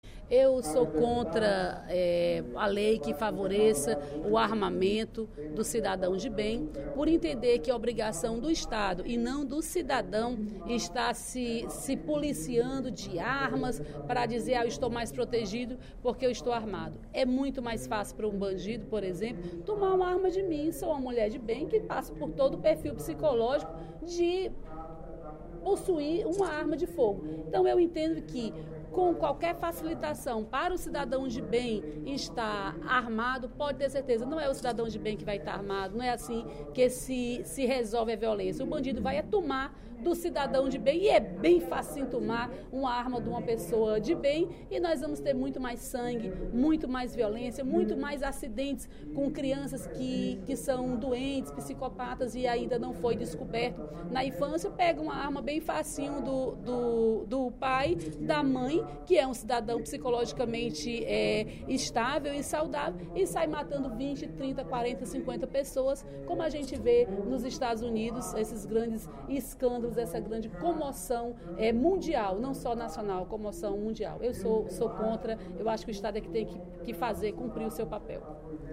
A deputada Dra. Silvana (PMDB) disse, no primeiro expediente da sessão plenária desta quarta-feira (04/11), ser contra o projeto de lei, aprovado na Câmara Federal, que procura facilitar a aquisição de armas de fogo pelo cidadão brasileiro.